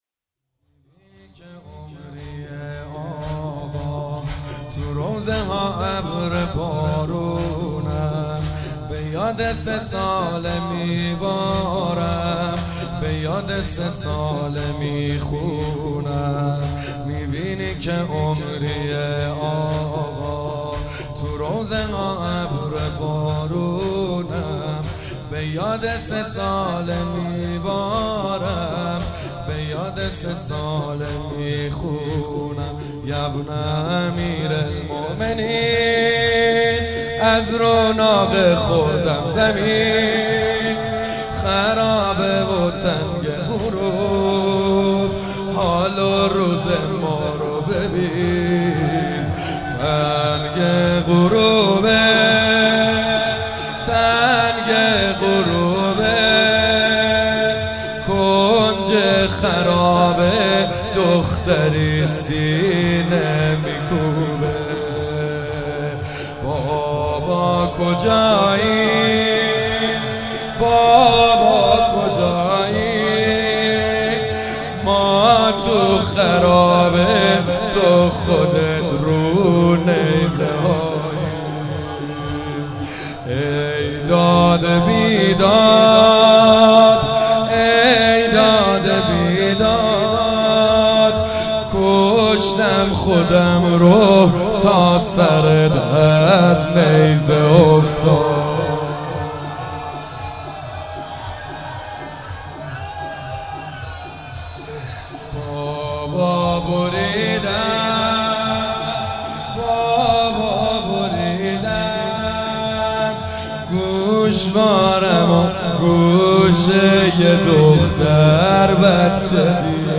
هئیت رزمندگان غرب تهران/رمضان96